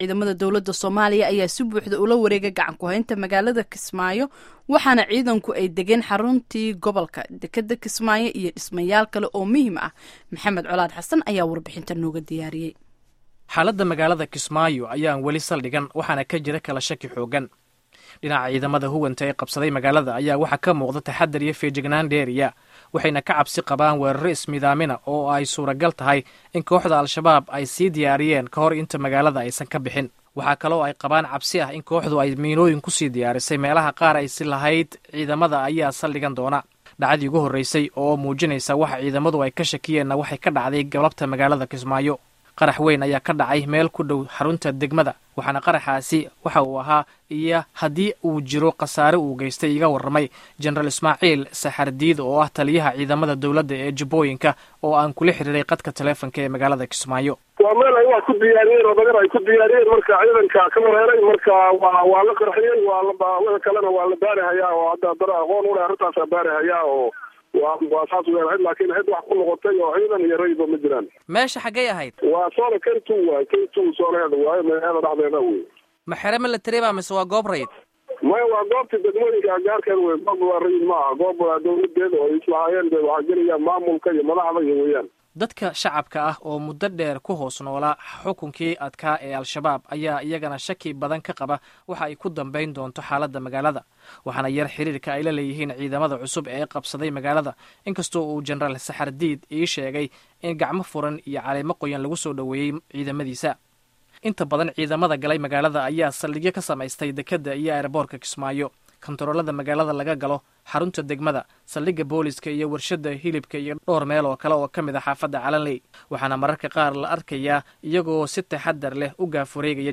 Embed share Dhageyso warbixinta xaaladda Kismayo by VOA Embed share The code has been copied to your clipboard.